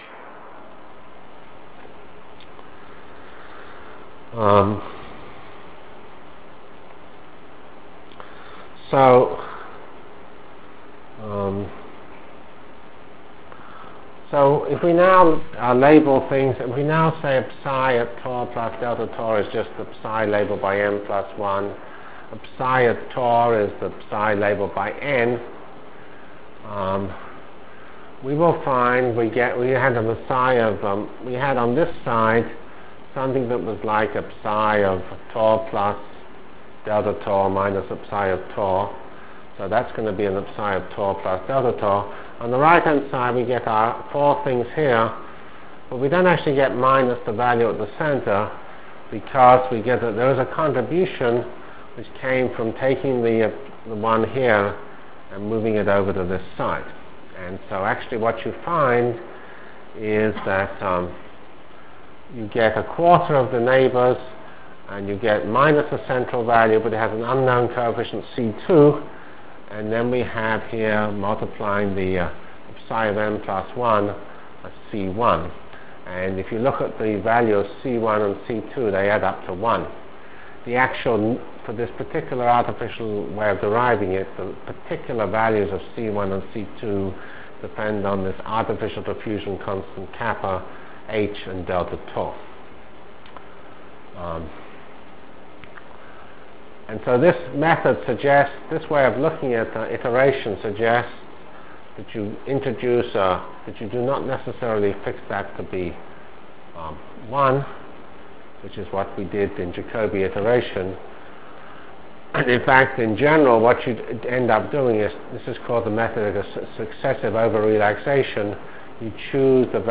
From CPS615-Basic PDE Solver Discussion and Sparse Matrix Formulation Delivered Lectures of CPS615 Basic Simulation Track for Computational Science -- 8 November 96.